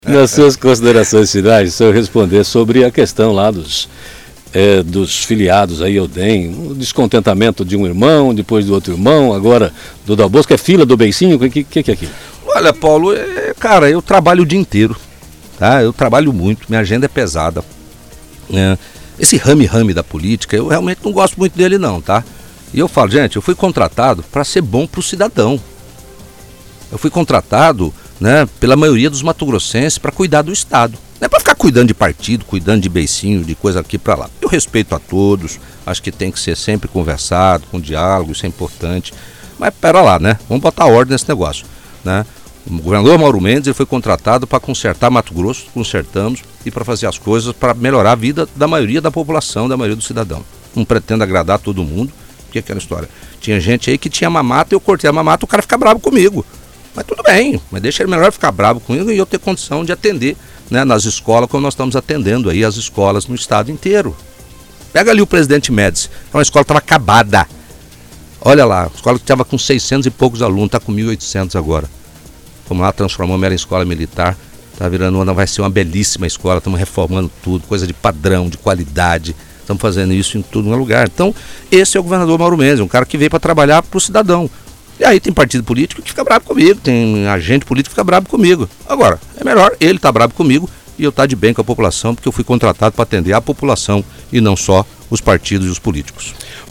Ouça o áudio com a entrevista do governador Mauro Mendes à Rádio Jovem Pan que deu início a toda polêmica.